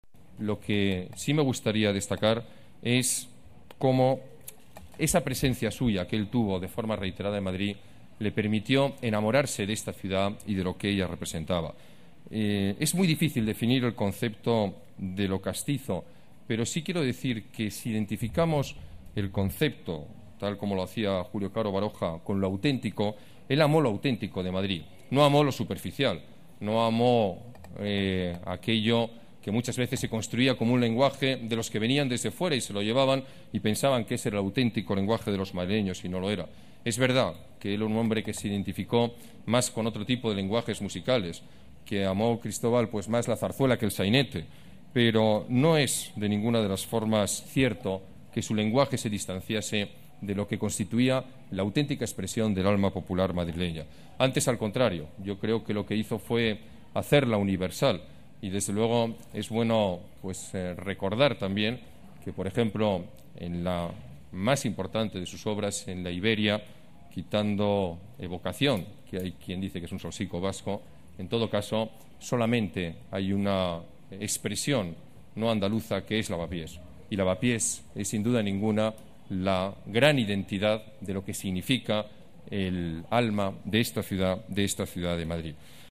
Nueva ventana:Alcalde, Alberto Ruiz-Gallardón en el descubrimiento de la placa de Albéniz